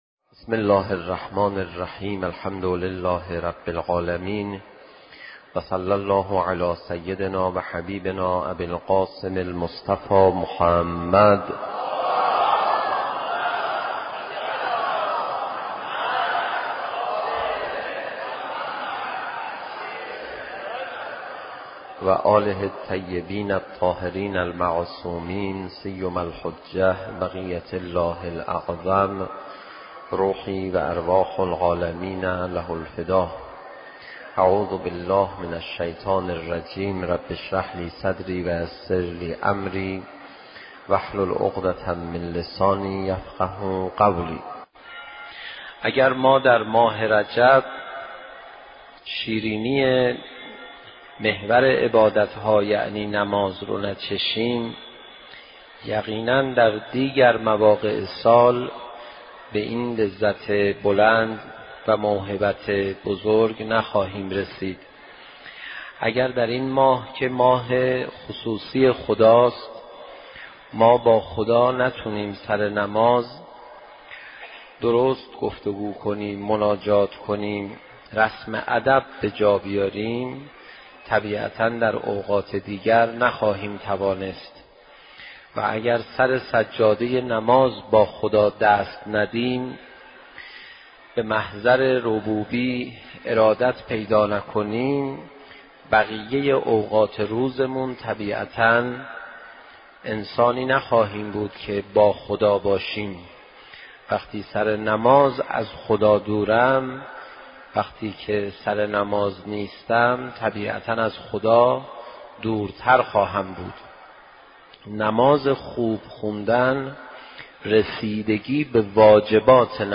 سخنرانی حجت الاسلام پناهیان درمورد ویژگی نماز خوب